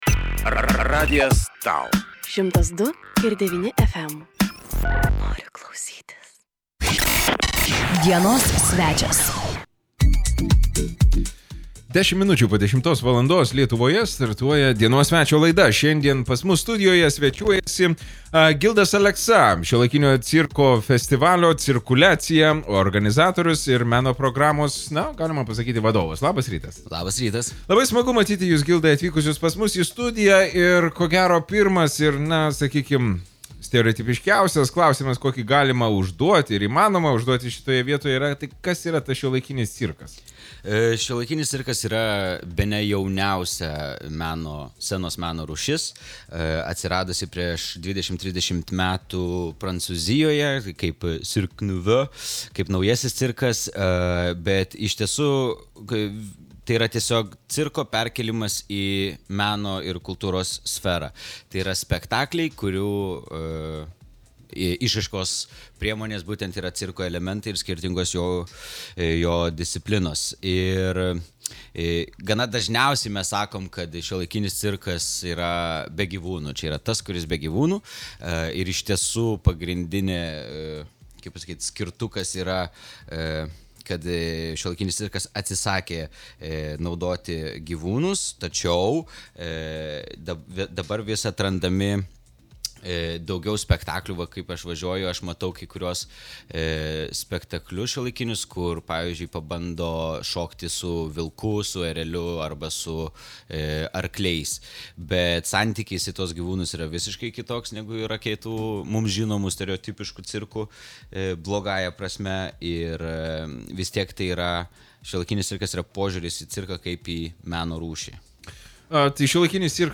POKALBIAI SU SVEČIAIS
CIRKULIACIJA - studijoje